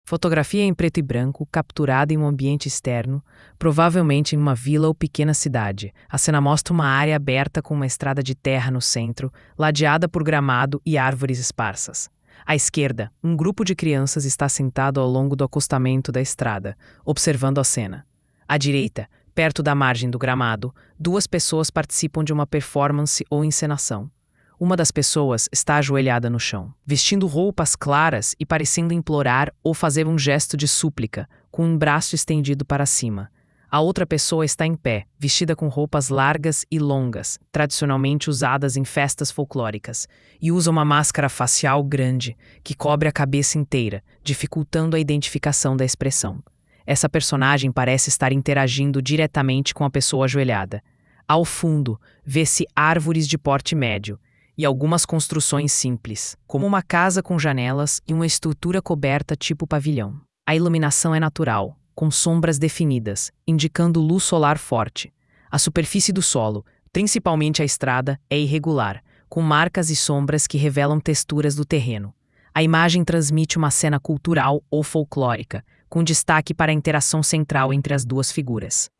QR code para acessar a audiodescrição da mídia Teatro Carroça de Mamulengo